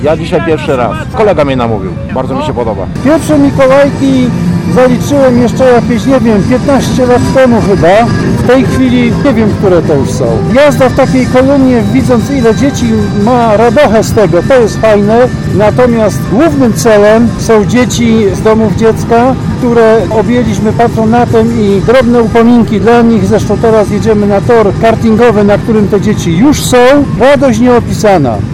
Tarnowski rynek zapełnił się nietypowo przystrojonymi motocyklami. Warkot silników tym razem wydobywał się spod rogów reniferów, a maszyn dosiadali Mikołaje.